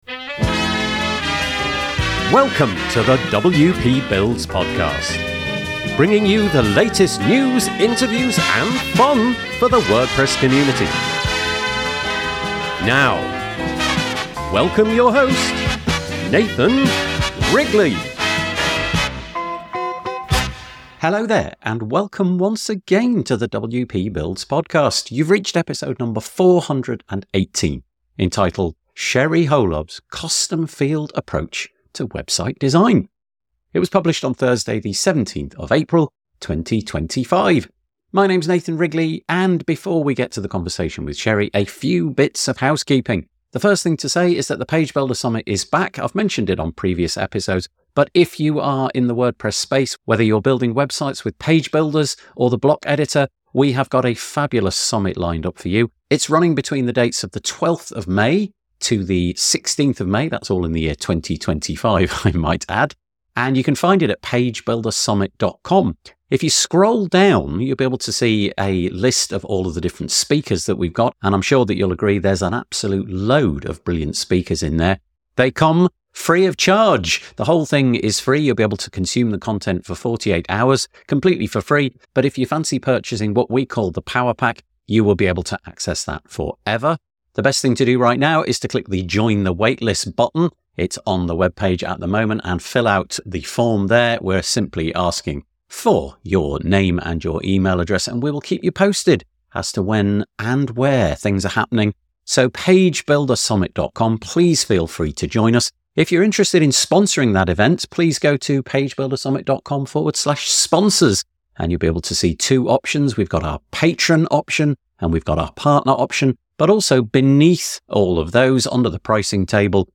The conversation also touches on the evolving nature of web design and WordPress's adaptability.